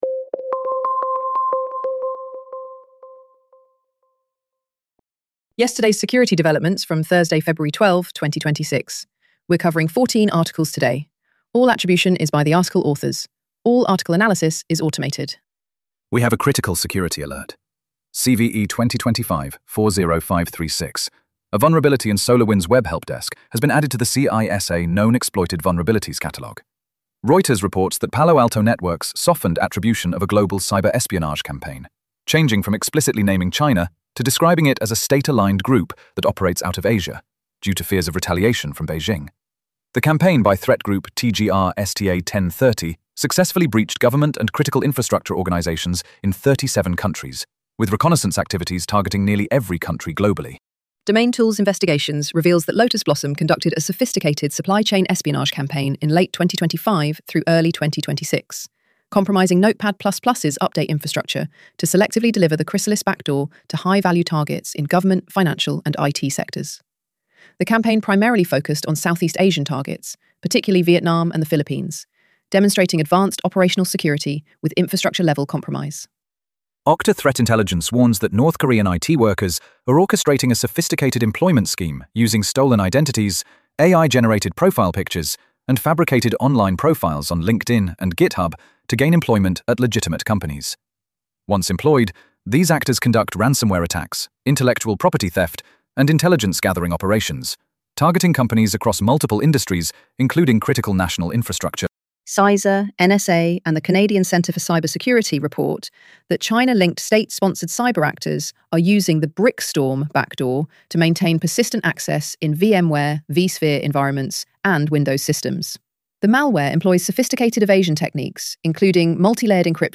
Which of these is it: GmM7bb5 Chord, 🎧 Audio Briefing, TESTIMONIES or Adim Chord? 🎧 Audio Briefing